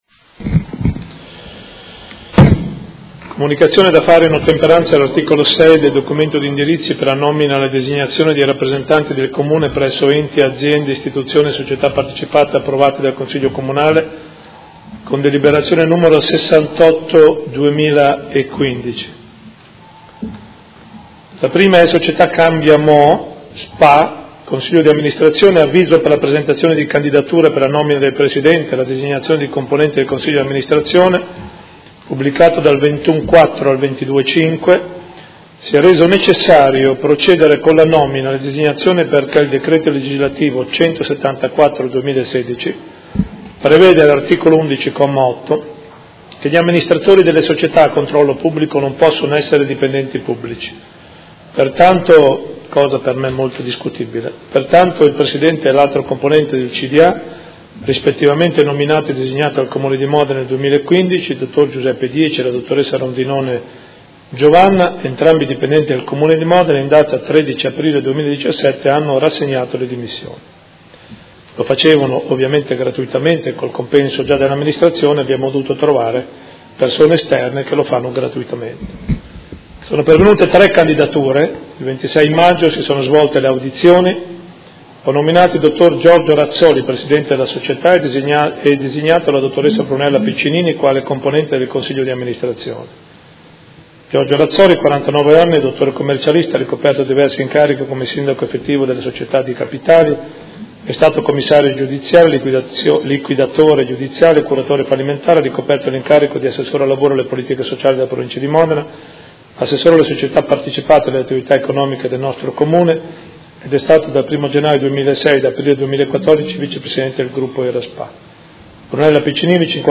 Seduta del 01/06/2017. Comunicazione del Sindaco su nuove nomine rappresentanti del Comune.